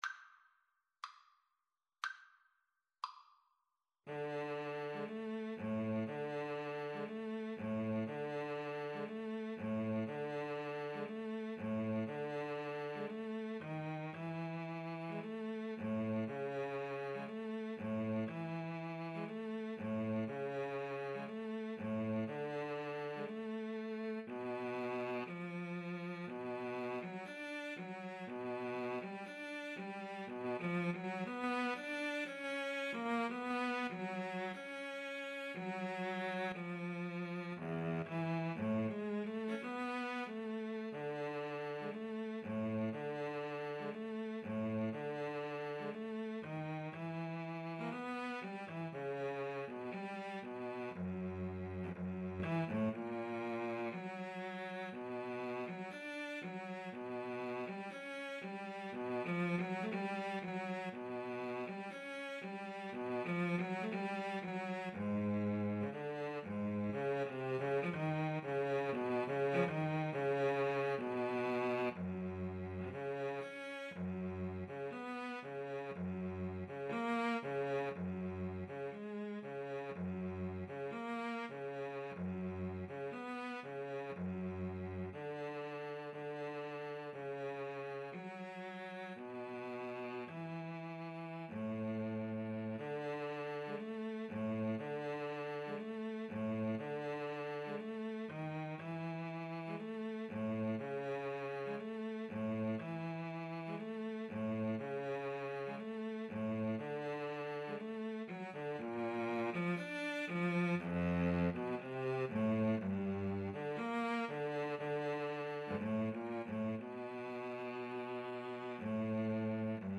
Free Sheet music for Violin-Cello Duet
ViolinCello
D major (Sounding Pitch) (View more D major Music for Violin-Cello Duet )
2/4 (View more 2/4 Music)
Classical (View more Classical Violin-Cello Duet Music)